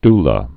(dlə)